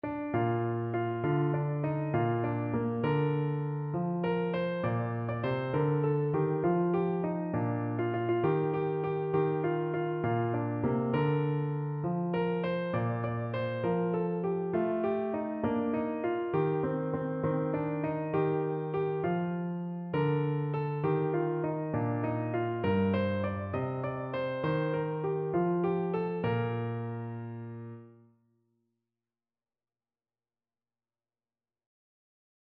No parts available for this pieces as it is for solo piano.
6/8 (View more 6/8 Music)
Piano  (View more Easy Piano Music)